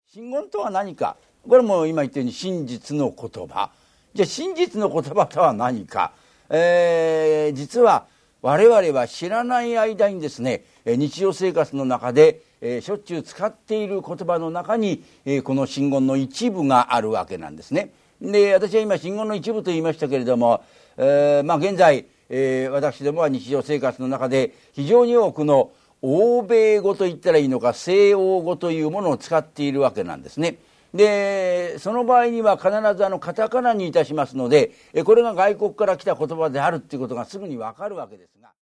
NHKラジオ第一で放送され、その後、
ラジオ第二「ラジオ深夜便」でも再放送された人気シリーズ。